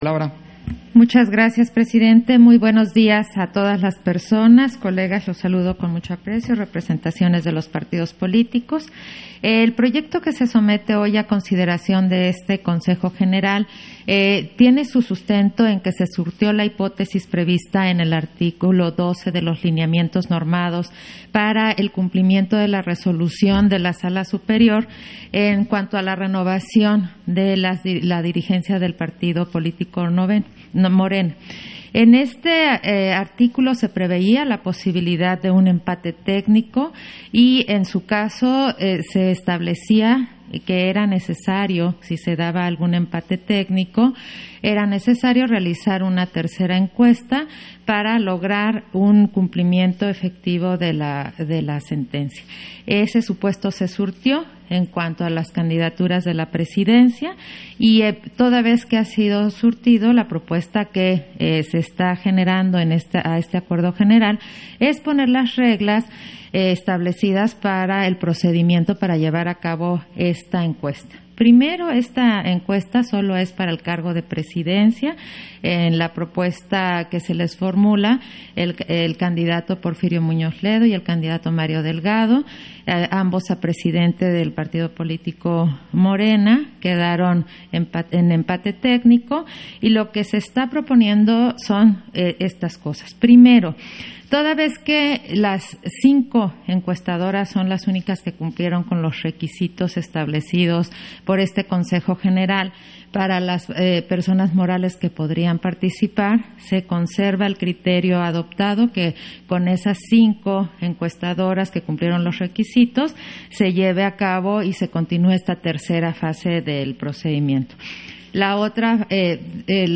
Intervención de Claudia Zavala, en en punto en que se ordena la realización de una nueva encuesta abierta para el cargo de presidencia del Comité Ejecutivo Nacional de Morena